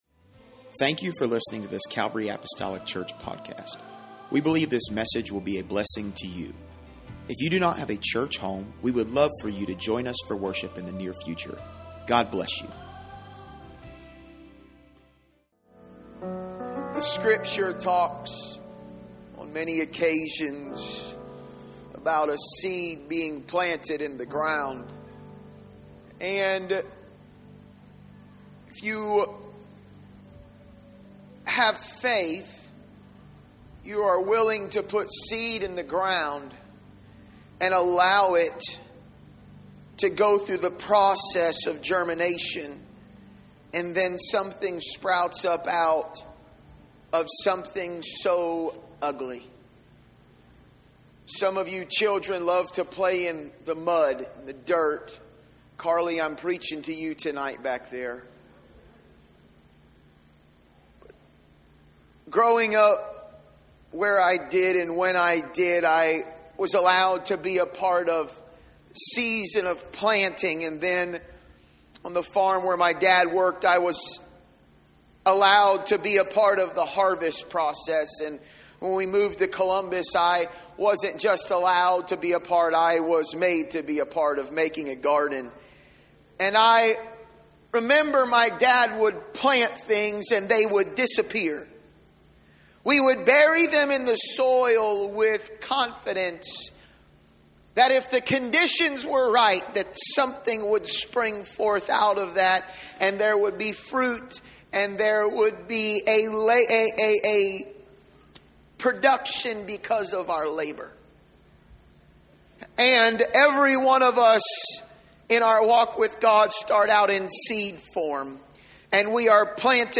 Roots Matter - The Calvary Apostolic Church Sermon Archive